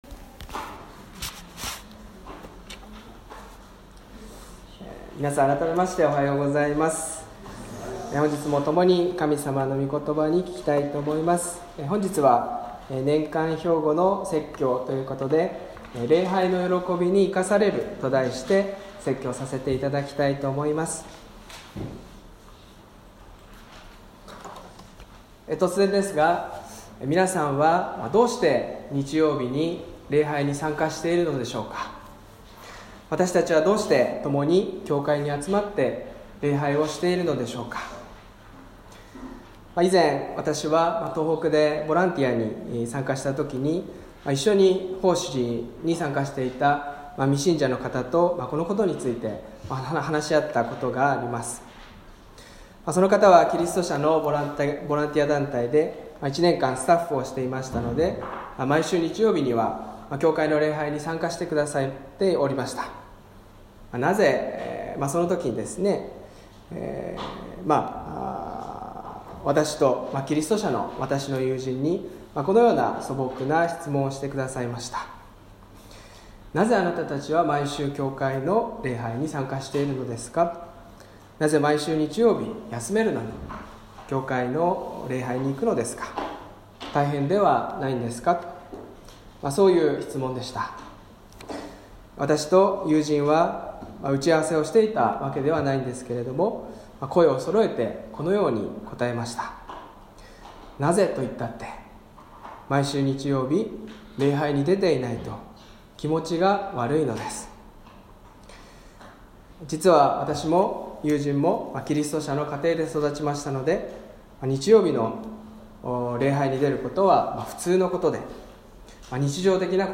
礼拝メッセージ | 銚子栄光教会